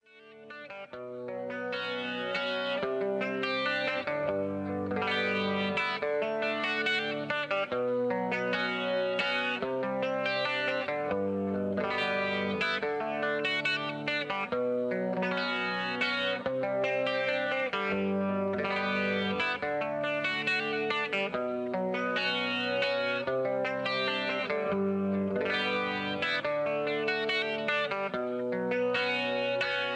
backing tracks
karaoke
rock